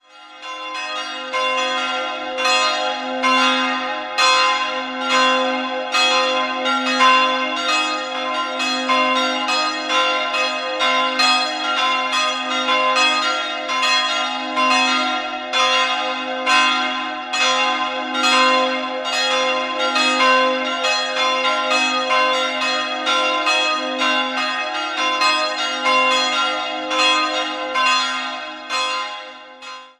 3-stimmiges TeDeum-Geläute: c''-es''-f'' Die Glocken wurden 1956 von Friedrich Wilhelm Schilling in Heidelberg gegossen.